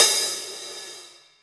paiste hi hat4 open.wav